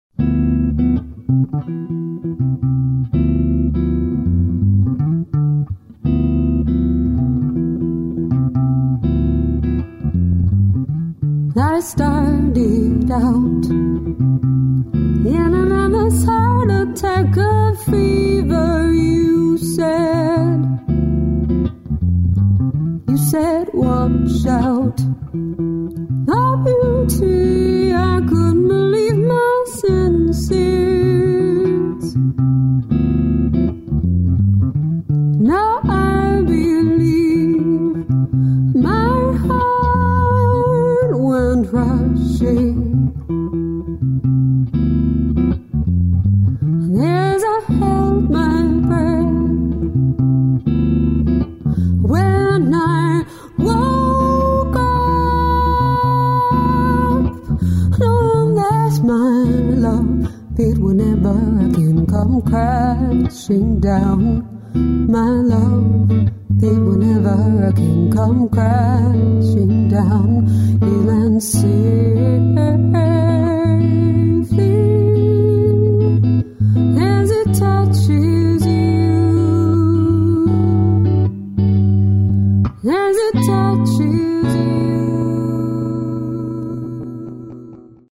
vocals
guitar